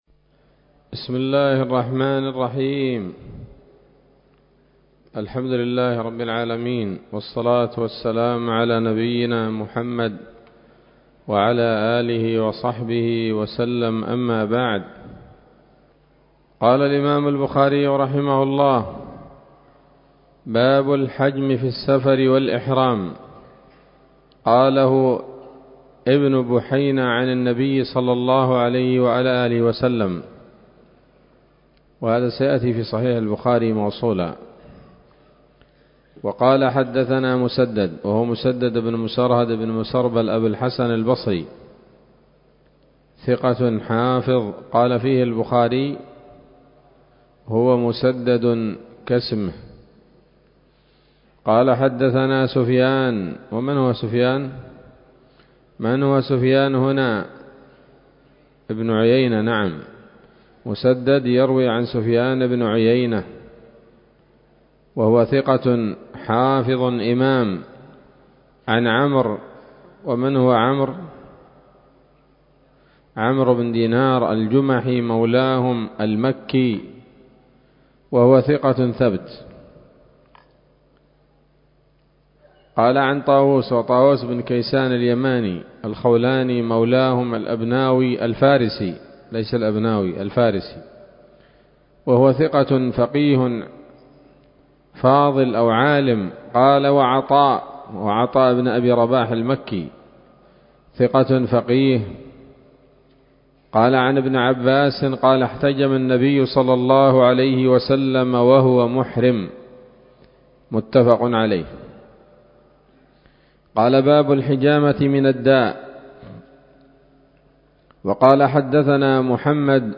الدرس التاسع من كتاب الطب من صحيح الإمام البخاري